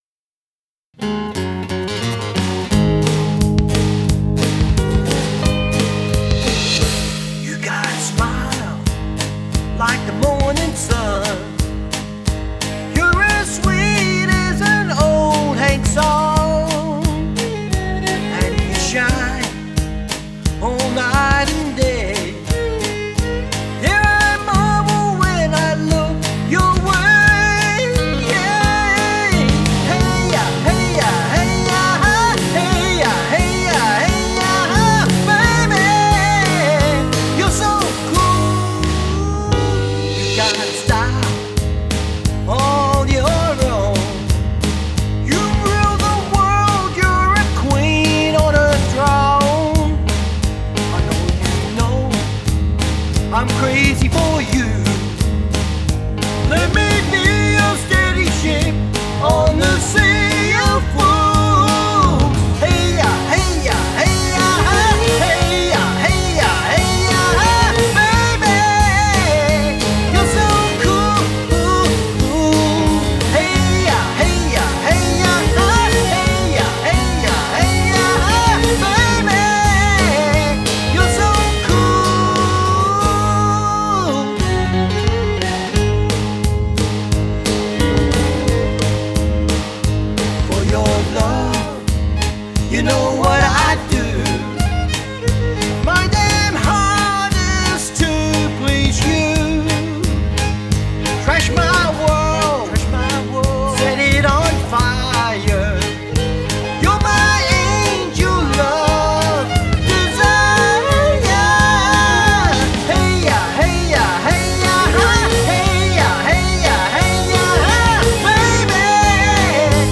Acoustic except Bass and Drum machine?
This song is a true homegrown tune recorded at home .